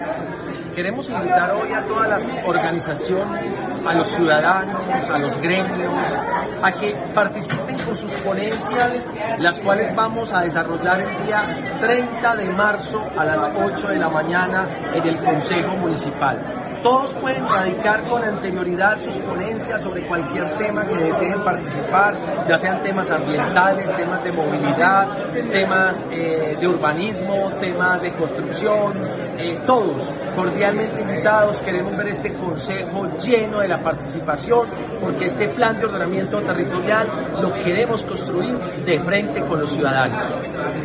Presidente-del-Concejo-Municipal-Walter-Rene-Molano-mp3cutnet.mp3